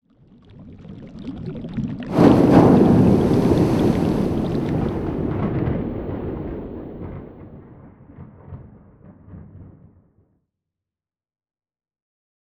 cauldron-done.wav